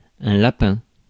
Ääntäminen
France: IPA: /la.pɛ̃/